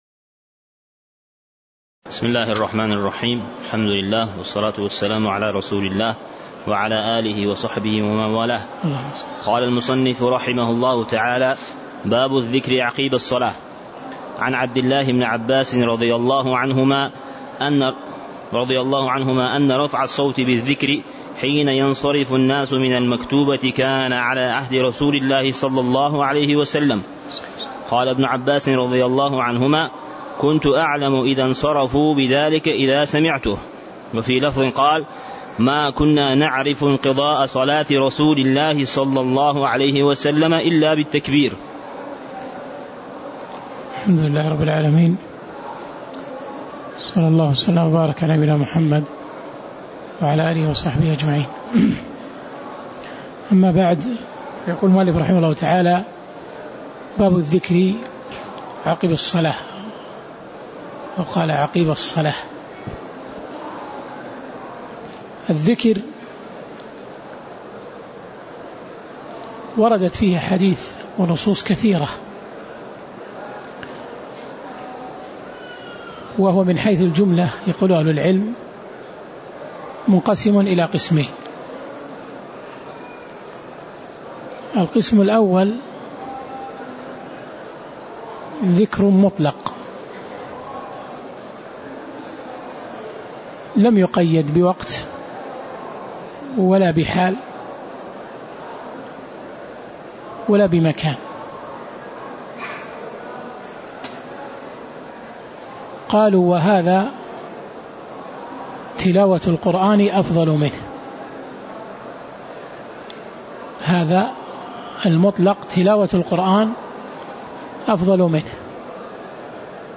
تاريخ النشر ١٣ جمادى الأولى ١٤٣٧ هـ المكان: المسجد النبوي الشيخ